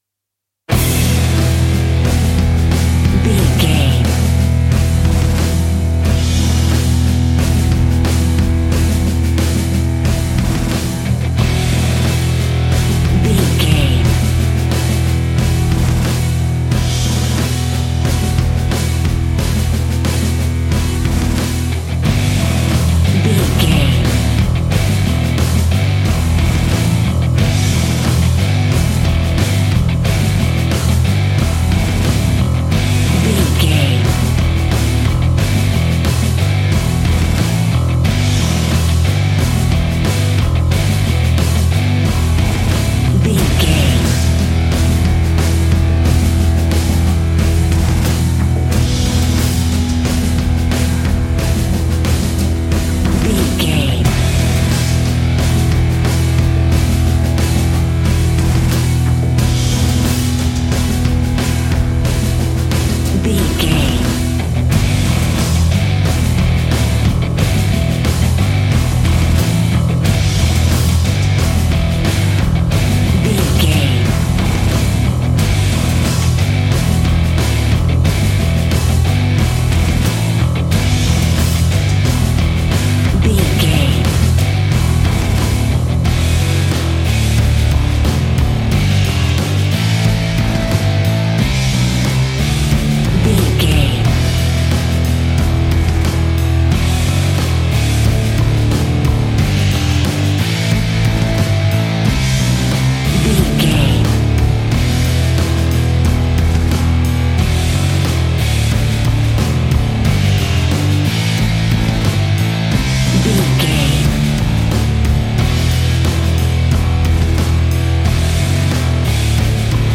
Epic / Action
Fast paced
Aeolian/Minor
hard rock
guitars
rock instrumentals
Heavy Metal Guitars
Metal Drums
Heavy Bass Guitars